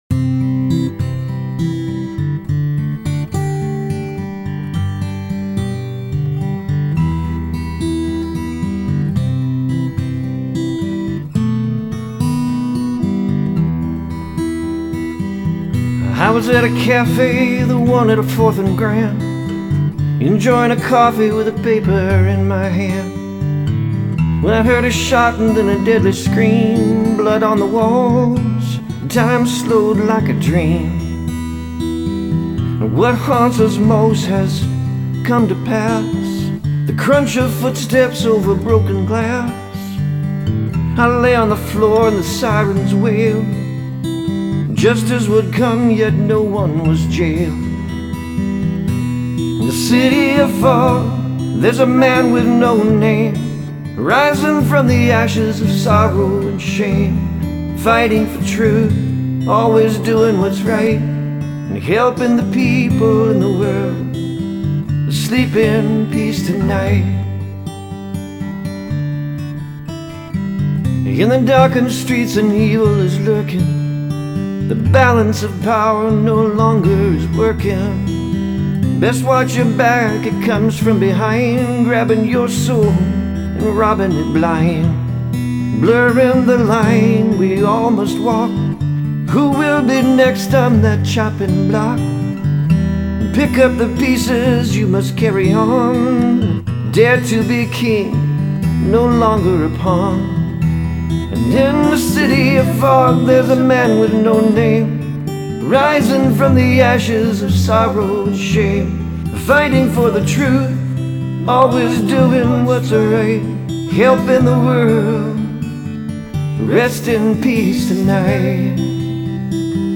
• This song sounds like a soft country song.